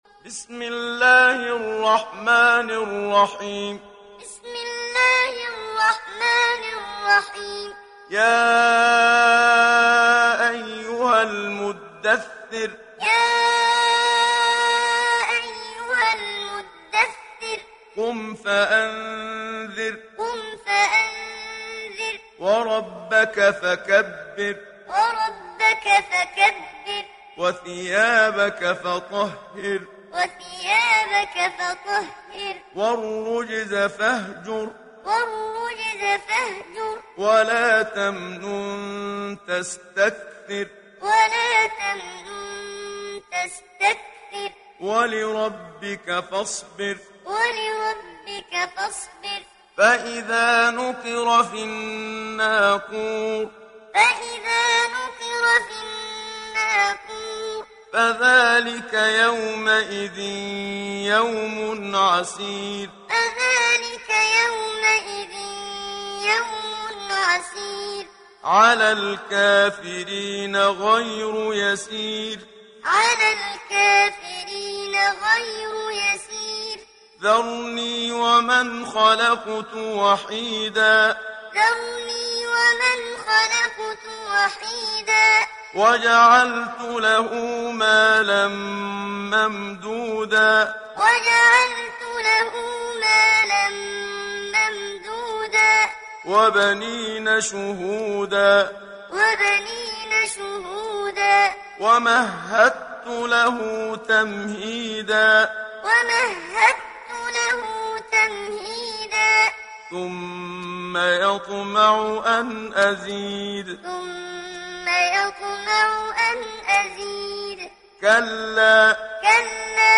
Sourate Al Muddathir Télécharger mp3 Muhammad Siddiq Minshawi Muallim Riwayat Hafs an Assim, Téléchargez le Coran et écoutez les liens directs complets mp3
Télécharger Sourate Al Muddathir Muhammad Siddiq Minshawi Muallim